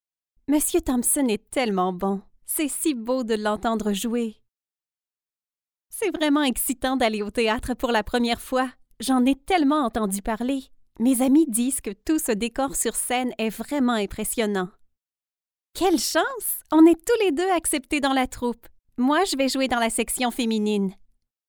Naturelle, Polyvalente, Amicale, Accessible, Fiable